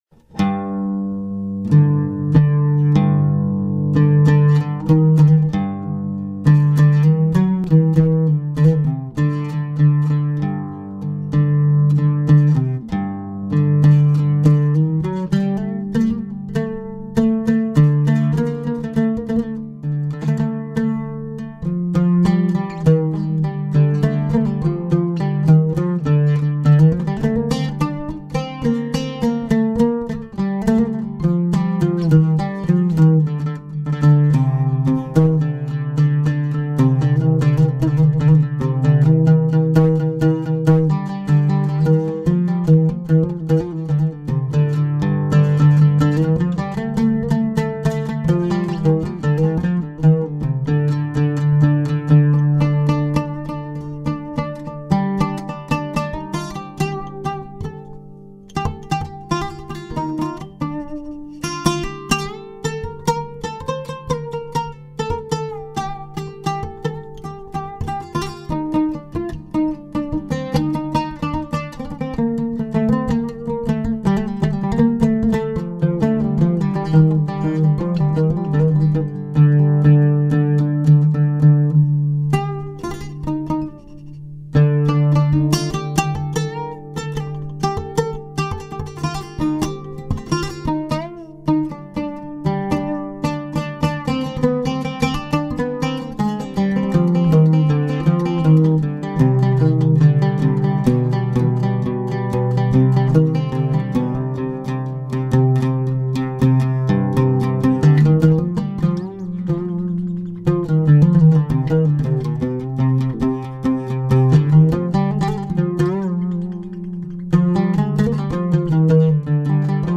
Here's an attempt to play, record and hopefully sent an audio file of the oud
This one is particularly warm sounding and almost 'cello like on the G and A.